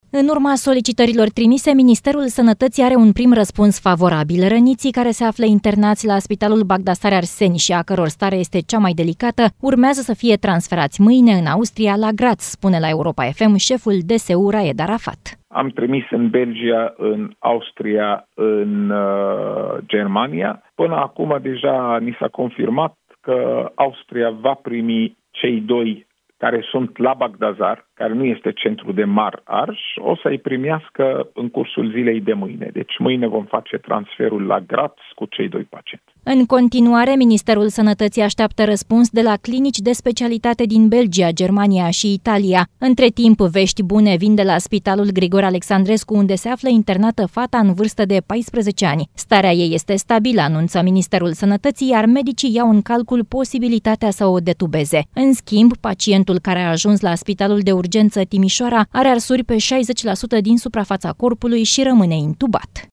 Răniții care se află internați la Spitalul Bagdsar-Arseni, a căror stare este cea mai delicată, urmează să fie transferați mâine în Austria la Graz, spune la Europa FM, șeful DSU, Raed Arafat.